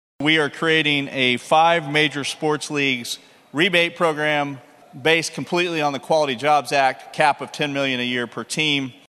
CLICK HERE to listen to commentary from Senate President Pro Tem Greg Treat.